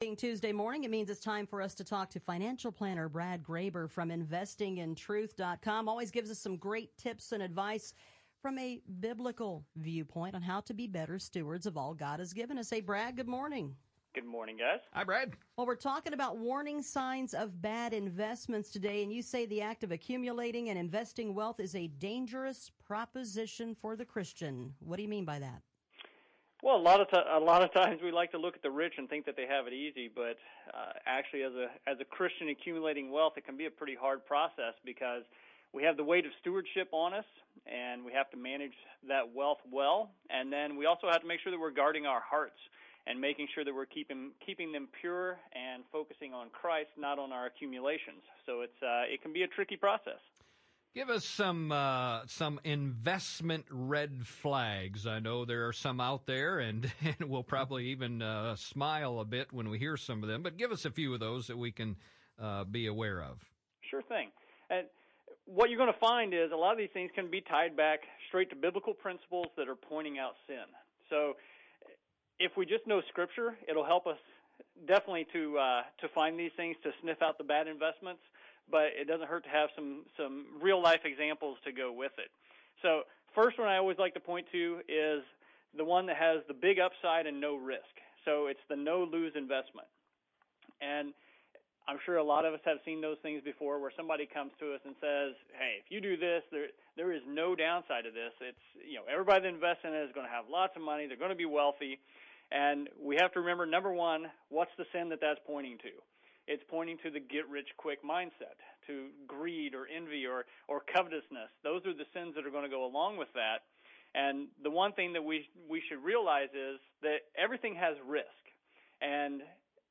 Moody Radio Interview – Warning Sings of Bad Investments